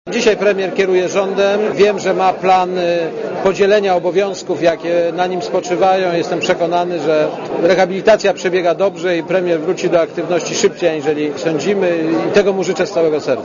Posłuchaj prezydenta Kwaśniewskiego (52kB)